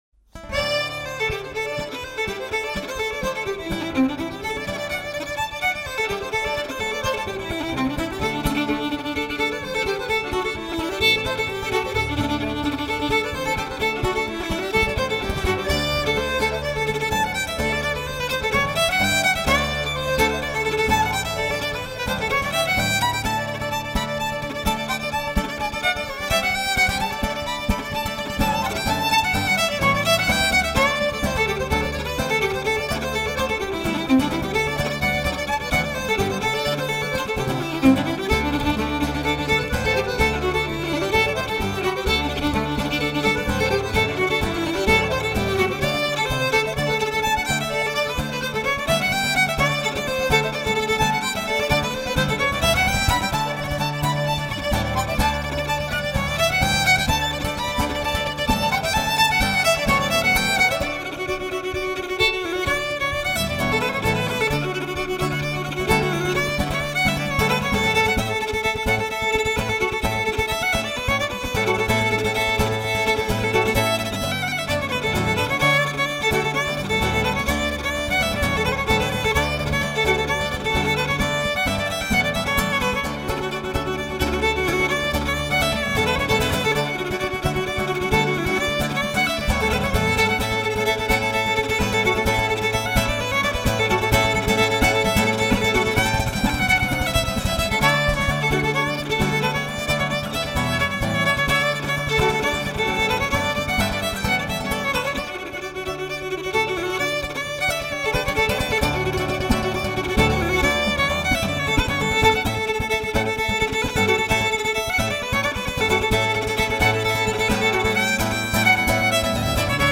Fiddle.
Guitar.